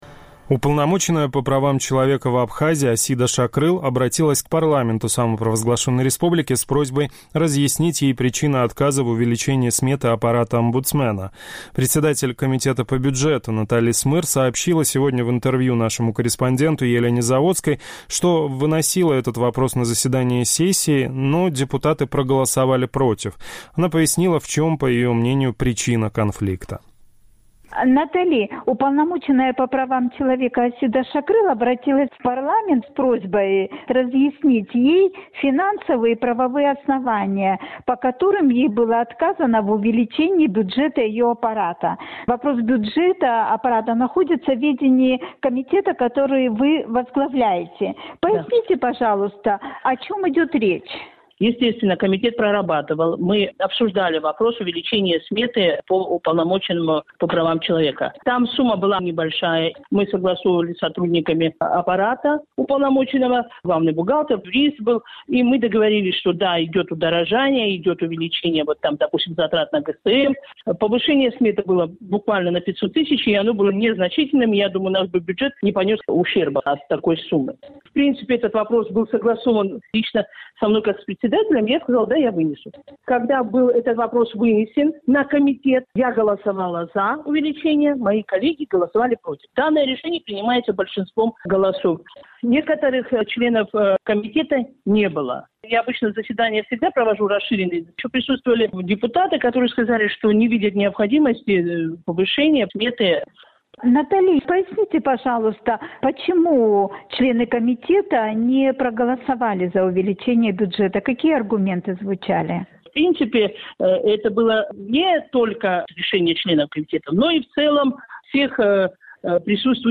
Уполномоченная по правам человека в Абхазии Асида Шакрыл обратилась к парламенту республики с просьбой разъяснить ей причины отказа в увеличении сметы аппарата омбудсмена. Председатель комитета по бюджету Натали Смыр сообщила в интервью «Эху Кавказа», что вынесла этот вопрос на заседание сессии,...